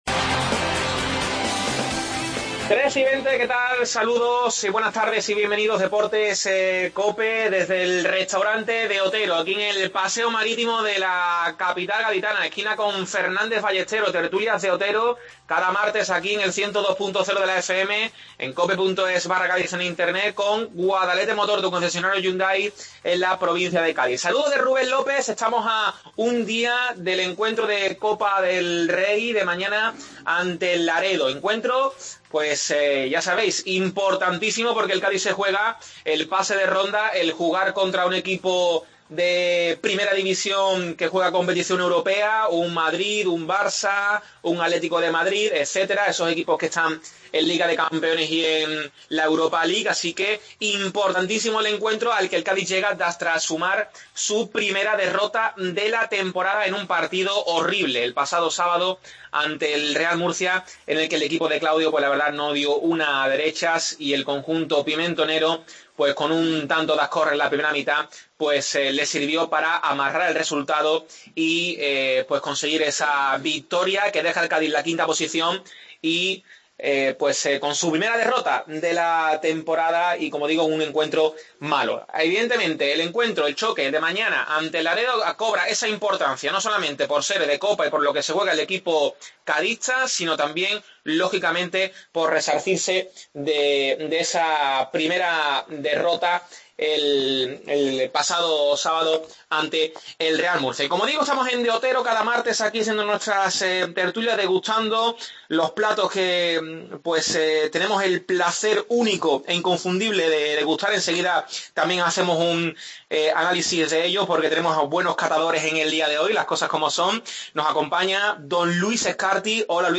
AUDIO: Desde el Restaurante De Otero, las tertulas de Deportes Cope.